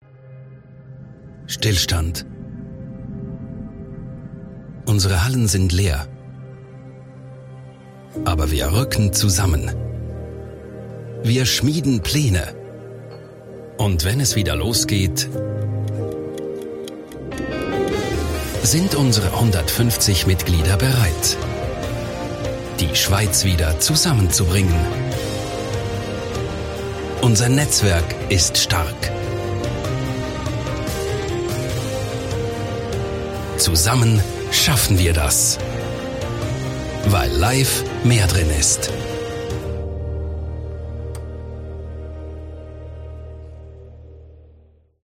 Werbung Hochdeutsch (CH)